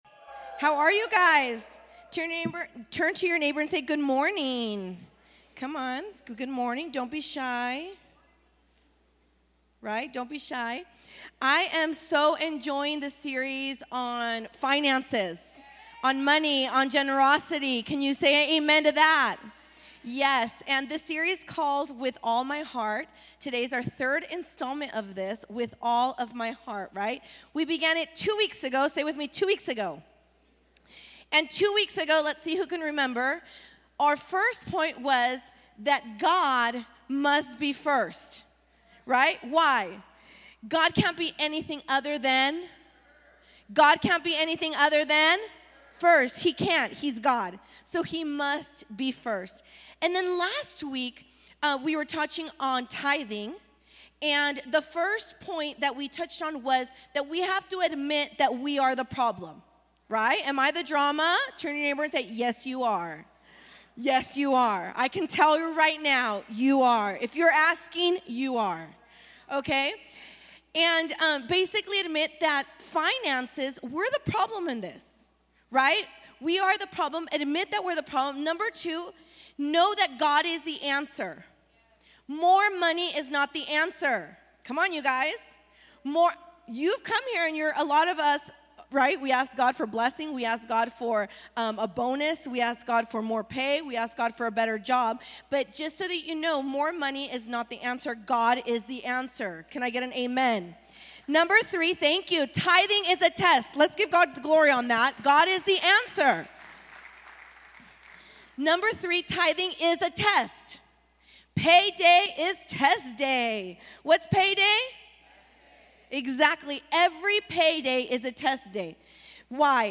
English Sermons